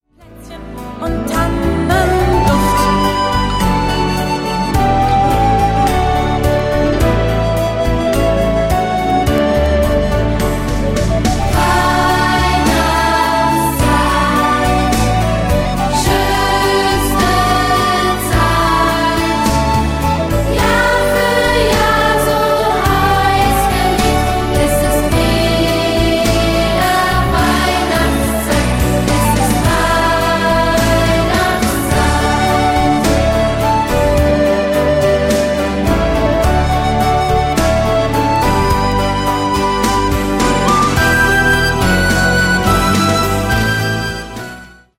• Stil/Genre: Playback ohne Backings